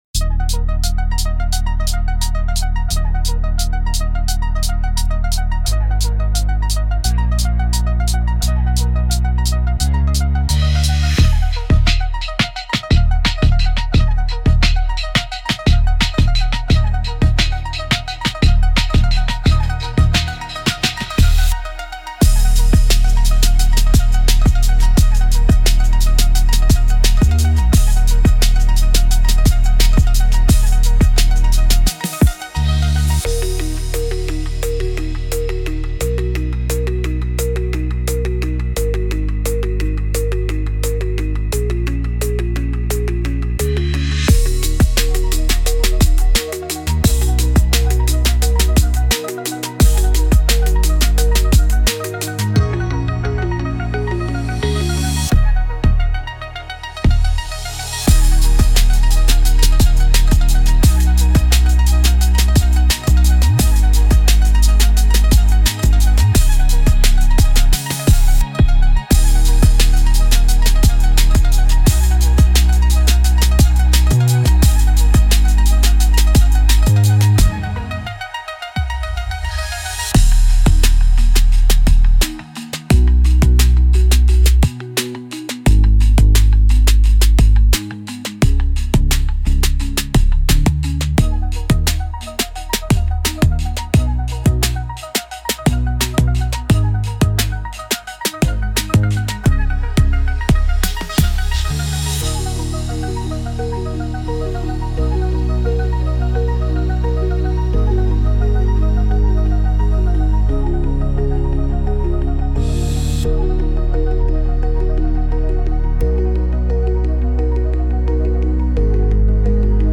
Instrumental - Ritual of the Pulse - 4 mins